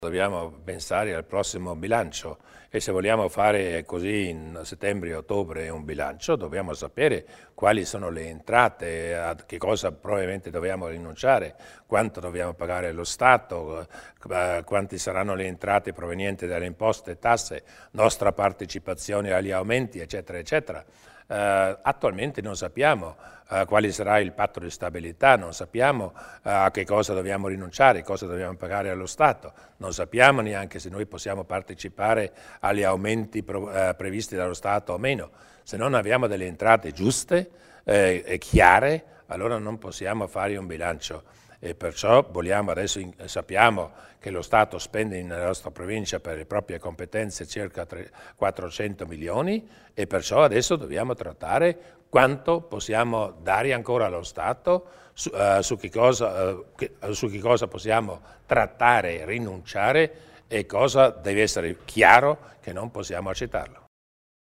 Il Presidente Durnwalder illustra i progetti di compartecipazione della Provincia al risanamento del deficit pubblico